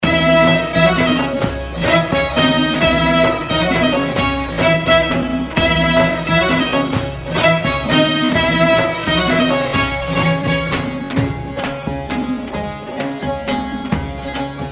آهنگ موبایل بی کلام و سنتی(رمانتیک)